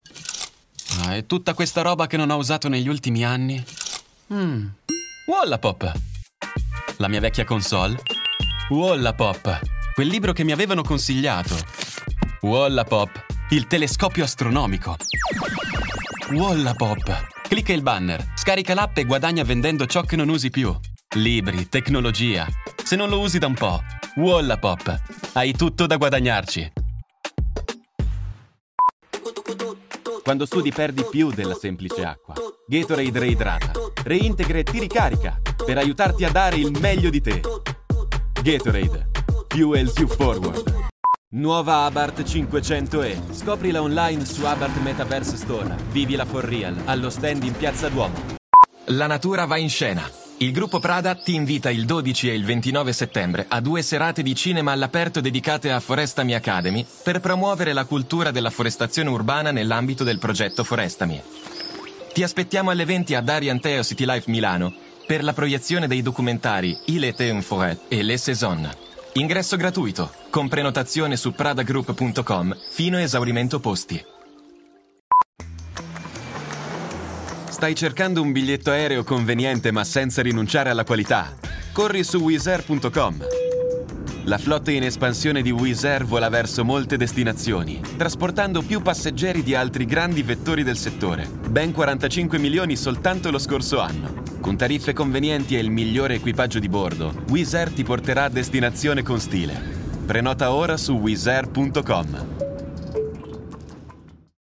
Voice Artists - Italian
New Commercial Reel for Wallapop, G....mp3